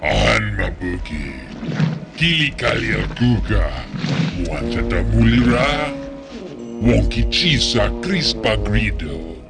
Jabba the Hutt speaking Huttese